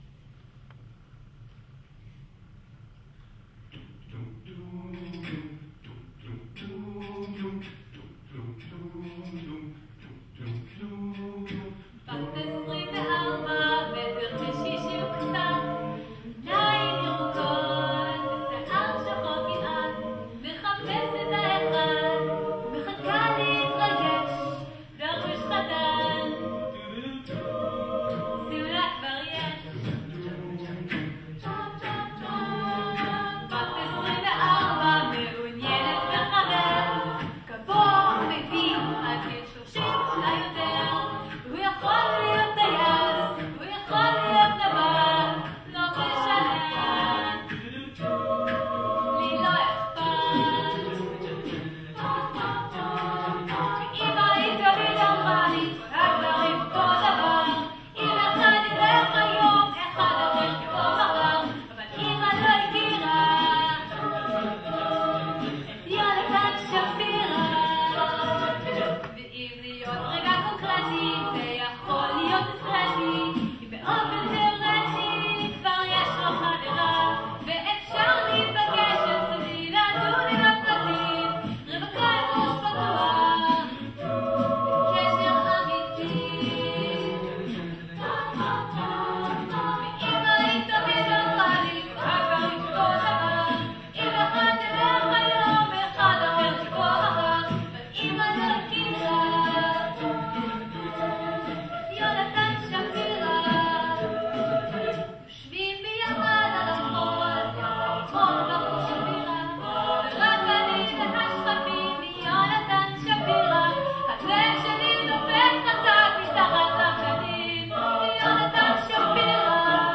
Click on the links to hear recordings from our concerts!